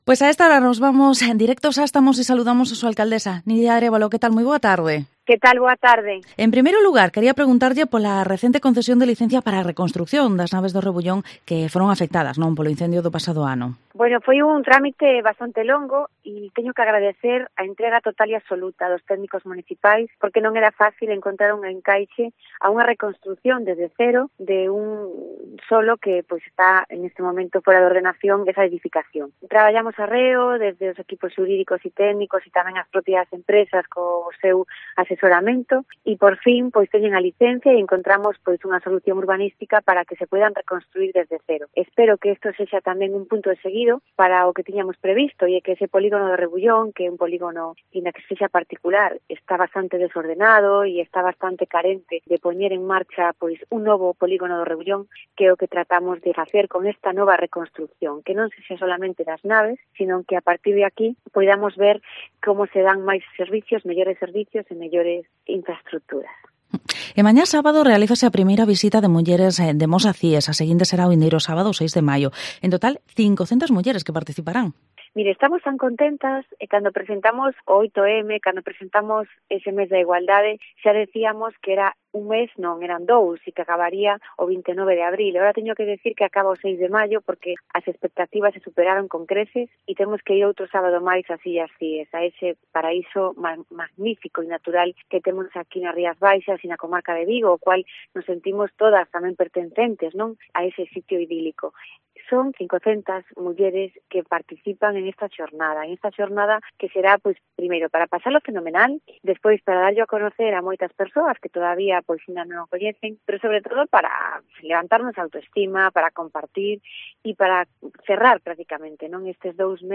Entrevista a la Alcaldesa de Mos, Nidia Arévalo